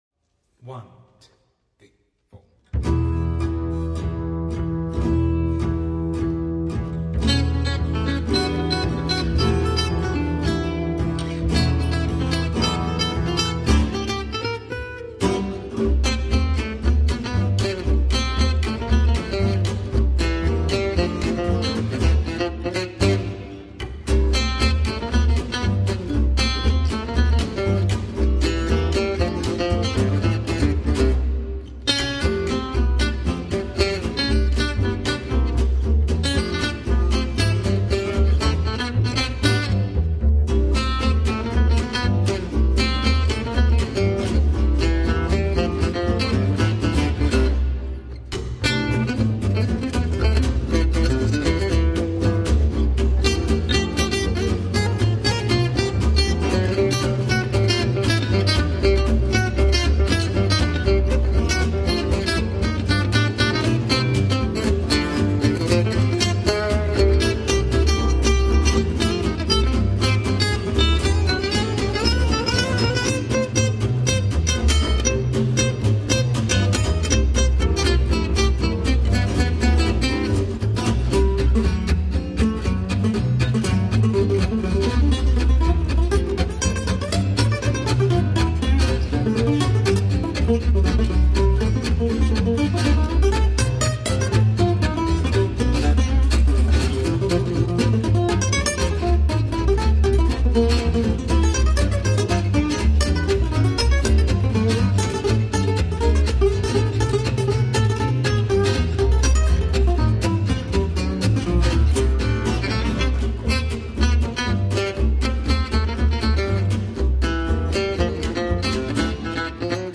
2 x Guitars, Double Bass